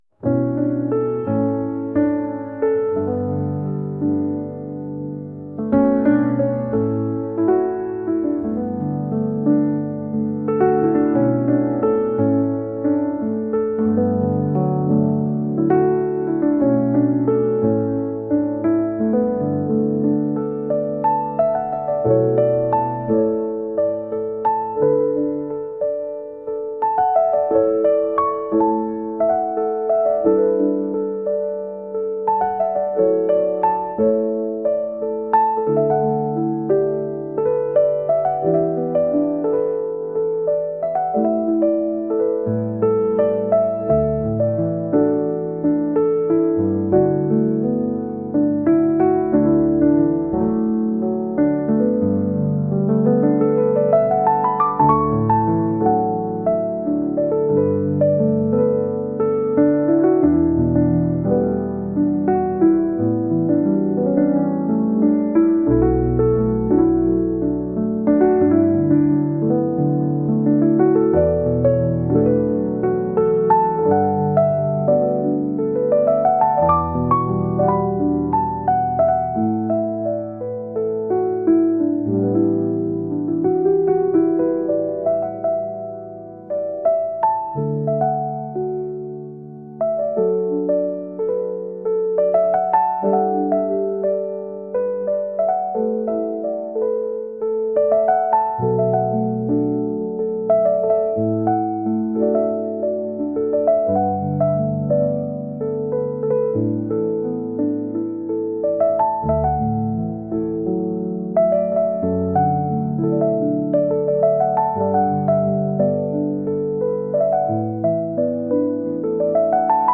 穏やかな中に懐かしさを感じるようなピアノ曲です。